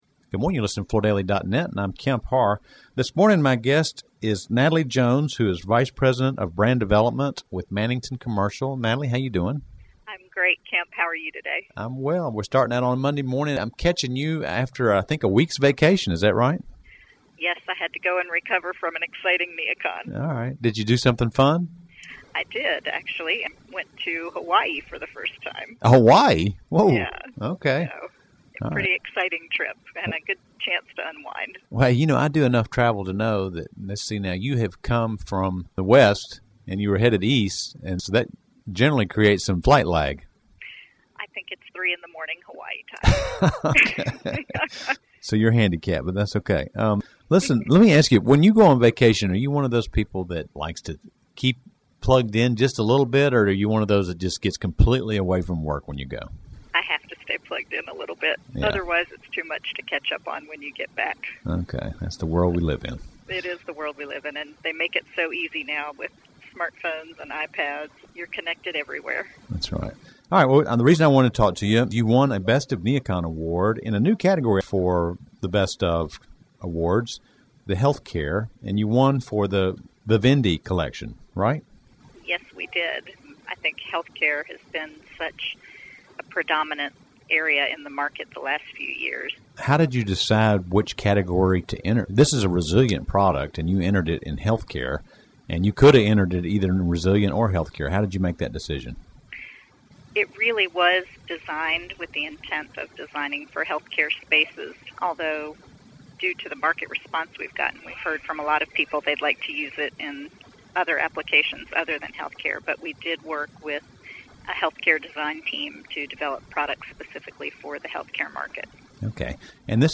Listen to the interview to hear details about Mannington's collaboration with HOK on this new product, plus more details about the traffic at NeoCon.